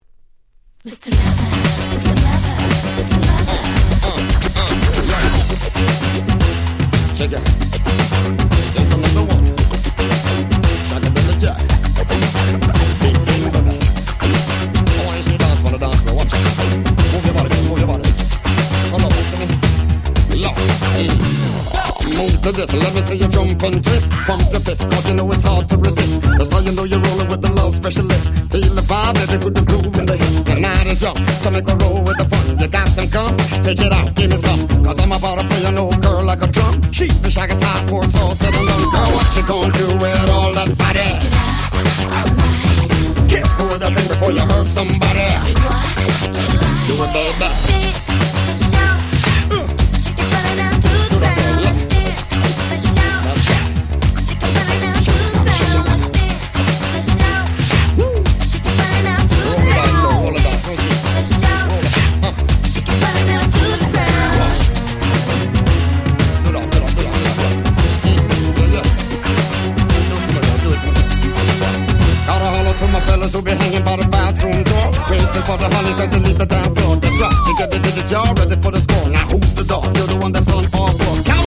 01' Super Hit Reggae !!